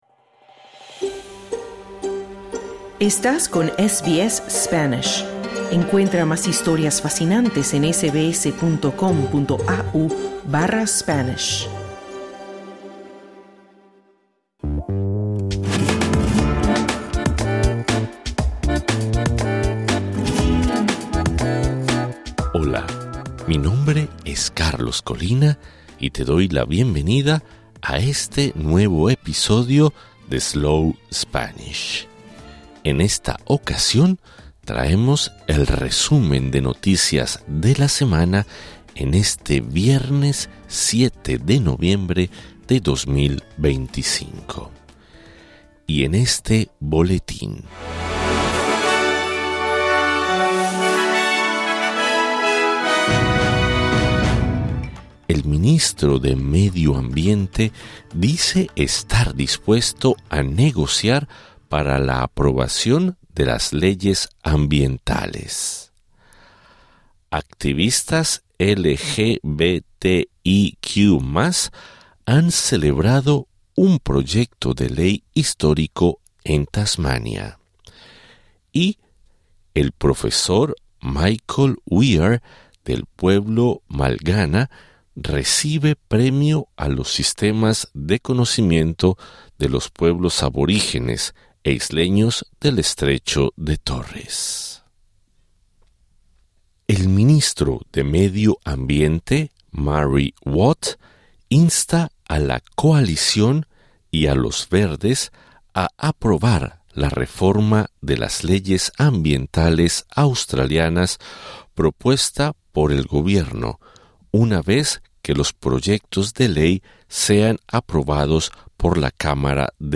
¡Hola! Welcome to SBS Slow Spanish, a new podcast designed in Australia specifically for those interested in learning the second most spoken language in the world. This is our weekly news flash in Spanish for November 7th, 2025.